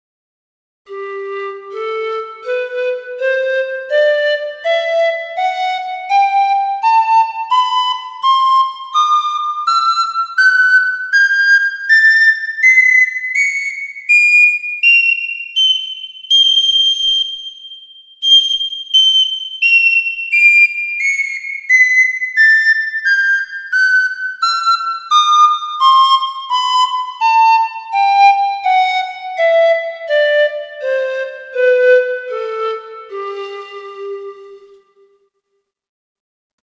Пан-флейта Gibonus FPS-Alto
Пан-флейта Gibonus FPS-Alto Тональность: G
Диапазон - три октавы (G1-G4), строй диатонический.